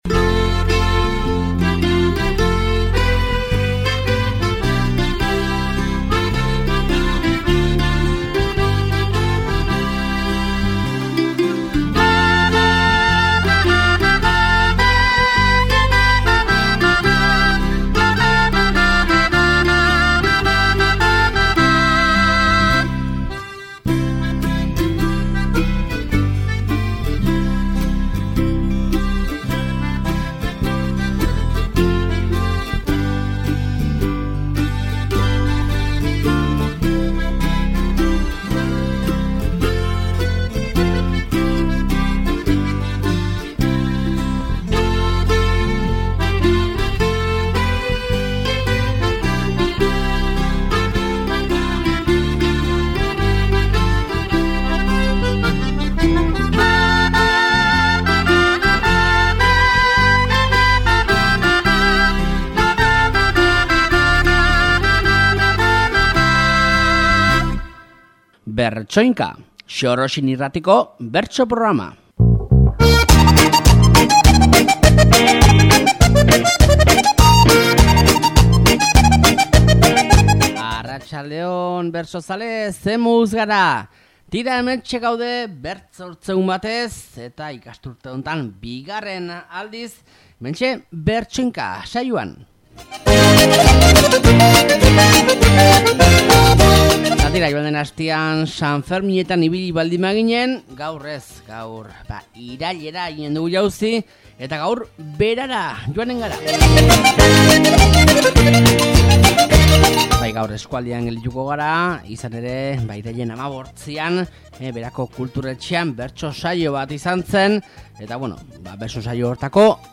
Iturengo Kontu Eguneko bertsoak eta Jon Lopategi zenari omenalditxoa